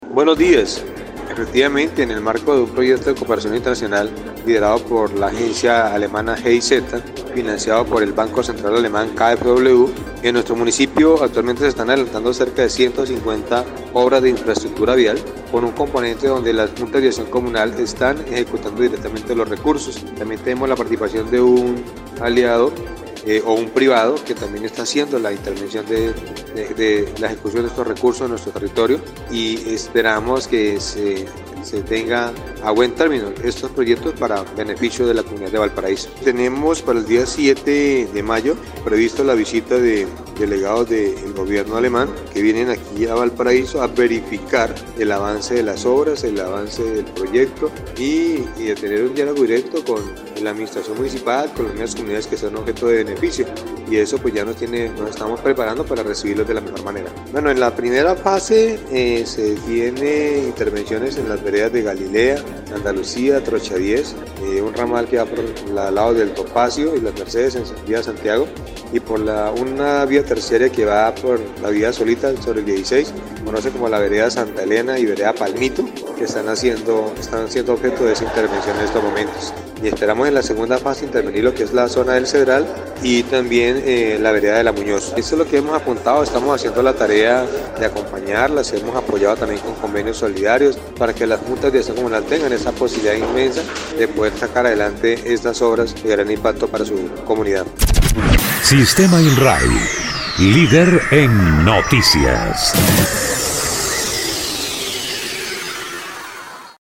Arbenz Pérez, alcalde del municipio de Valparaíso, explicó que dichos recursos y obras son ejecutadas en su totalidad por las mismas comunidades con lo cual se benefician zonas rurales como Galilea, Trocha 10, Topacio, Santa Helena y Palmito.
04_ALCALDE_ARBENZ_PEREZ_OBRAS.mp3